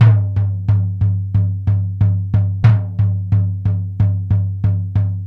Index of /90_sSampleCDs/Spectrasonics - Supreme Beats - World Dance/BTS_Tabla_Frames/BTS_Frame Drums